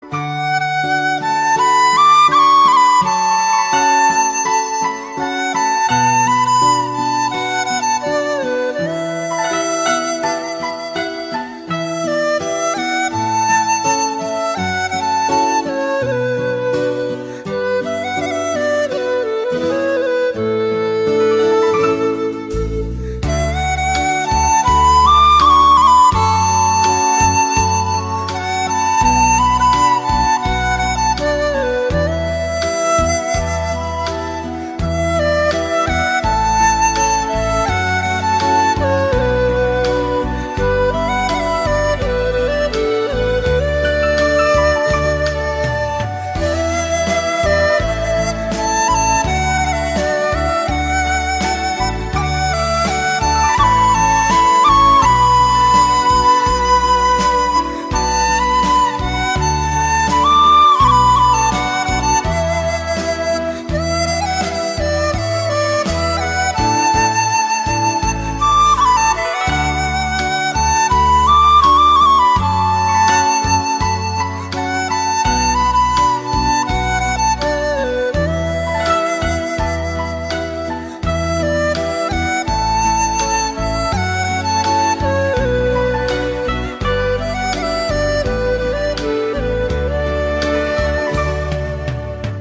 Giai điệu sáo trúc hoài niệm, sâu lắng về những kỷ niệm xưa.